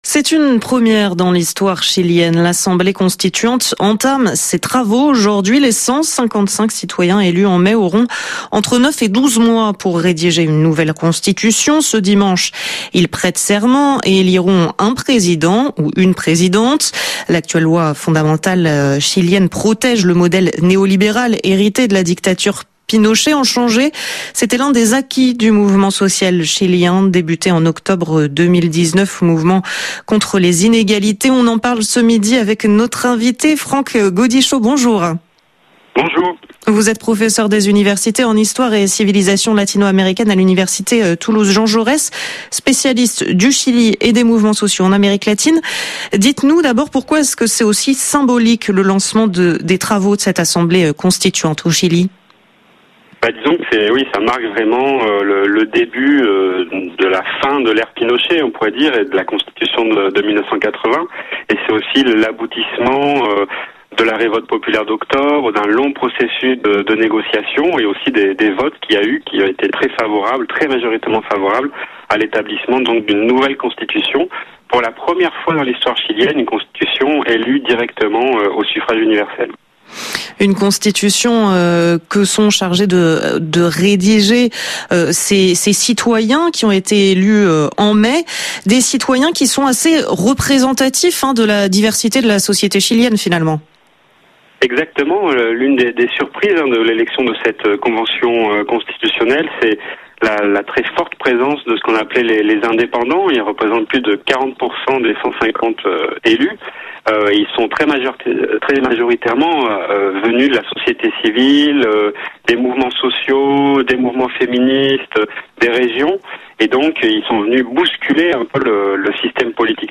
Invité international